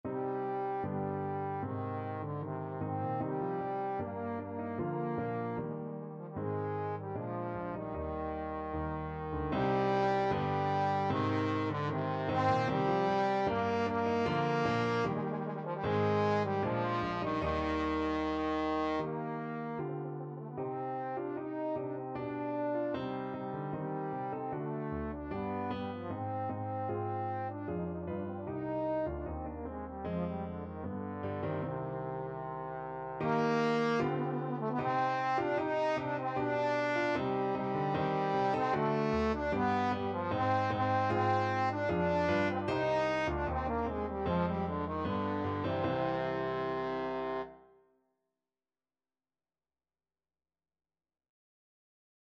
Trombone
C minor (Sounding Pitch) (View more C minor Music for Trombone )
2/2 (View more 2/2 Music)
Steadily =c.76
C4-Eb5
Classical (View more Classical Trombone Music)